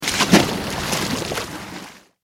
دانلود آهنگ دریا 1 از افکت صوتی طبیعت و محیط
جلوه های صوتی
دانلود صدای دریا 1 از ساعد نیوز با لینک مستقیم و کیفیت بالا